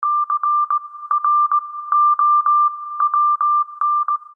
без слов